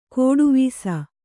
♪ kōḍu vīsa